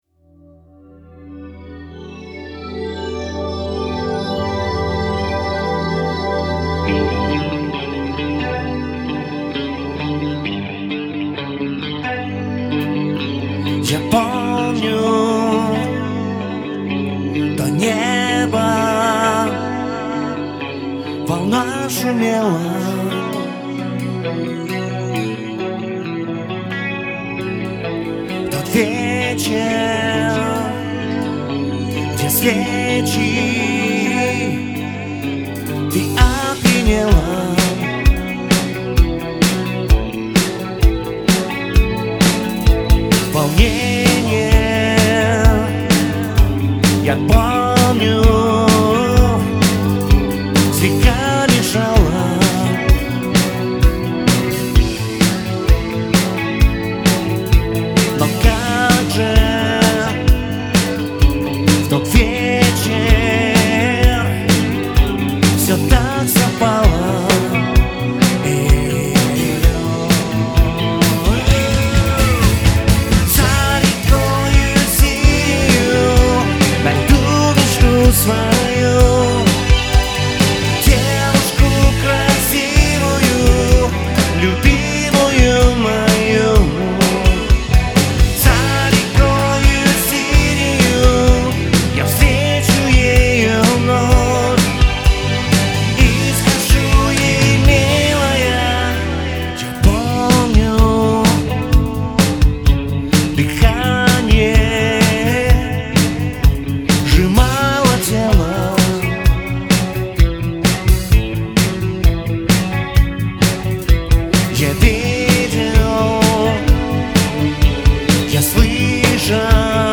Запись репы (Мультитрек) .
Это Live на репетиции...